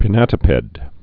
(pĭ-nătə-pĕd)